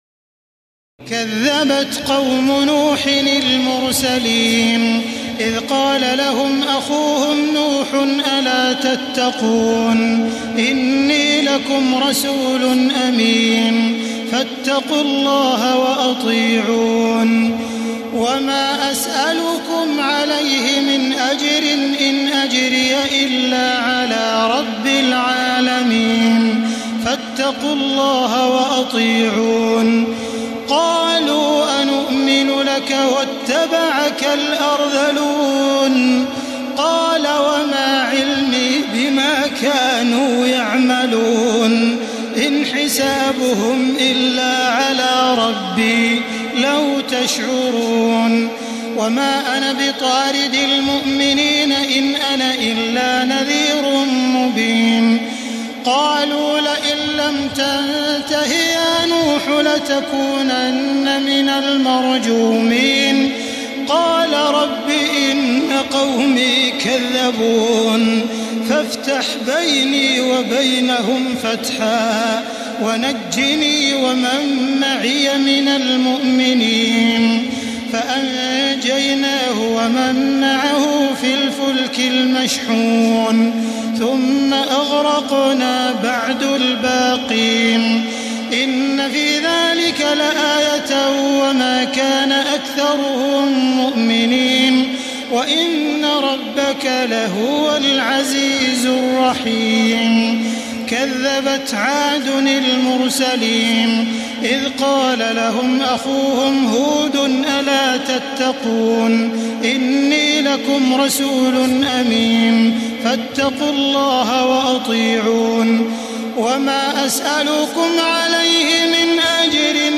تراويح الليلة التاسعة عشر رمضان 1435هـ من سورتي الشعراء (105-227) والنمل (1-58) Taraweeh 19 st night Ramadan 1435H from Surah Ash-Shu'araa and An-Naml > تراويح الحرم المكي عام 1435 🕋 > التراويح - تلاوات الحرمين